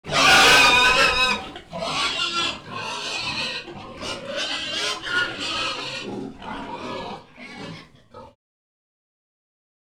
Index of /90_sSampleCDs/E-MU Producer Series Vol. 3 – Hollywood Sound Effects/Water/Pigs
PIG VERY 01R.wav